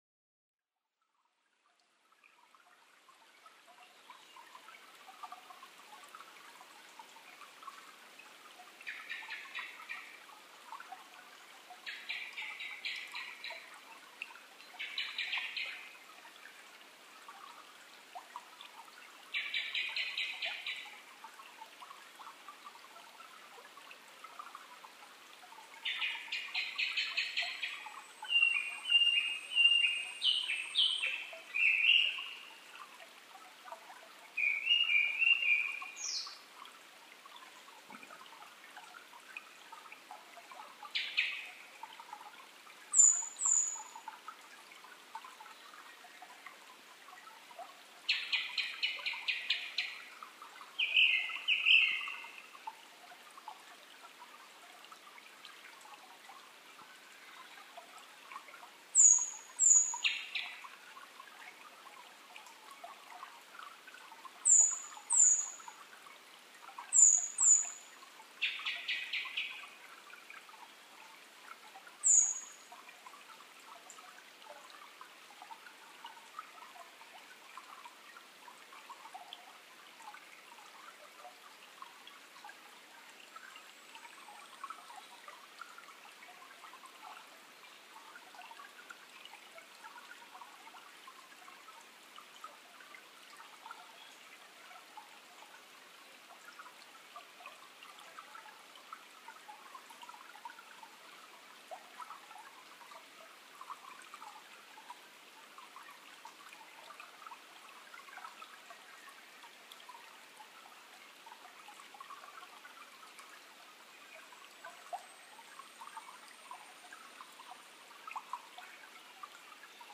クロツグミ　Turdus cardisツグミ科
日光市土呂部　alt=940m
Mic: Panasonic WM-61A  Binaural Souce with Dummy Head
暗くなる寸前の囀りです。
他の自然音：水音、ヤブサメ